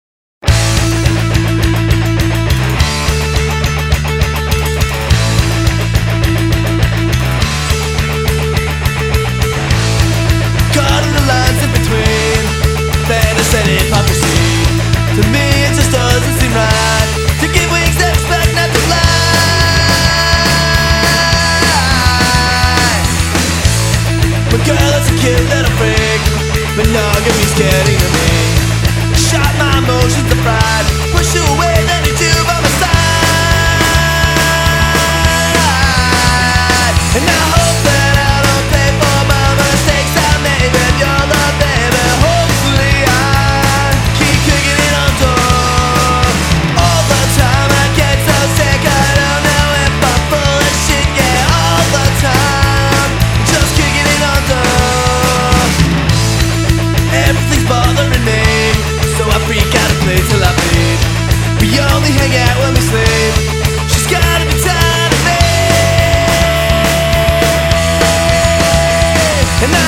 Guitar/Vocals
Bass/Backing Vocals
Drums